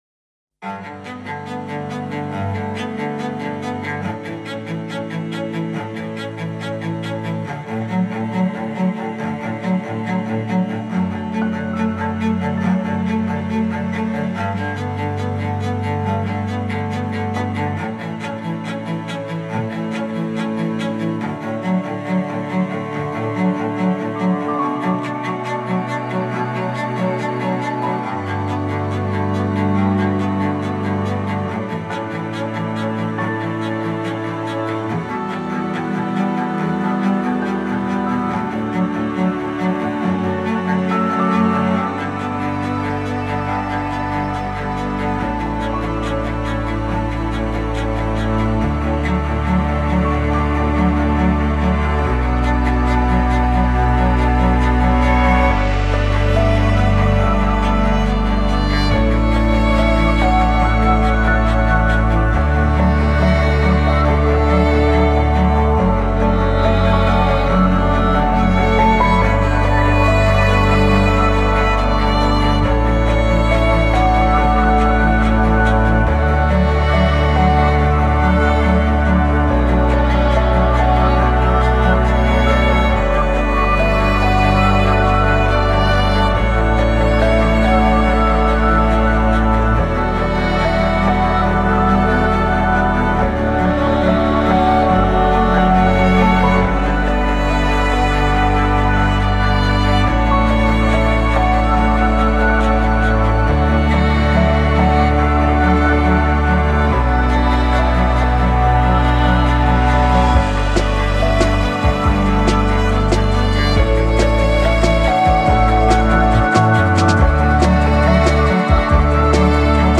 آرامش بخش , الهام‌بخش , پیانو , موسیقی بی کلام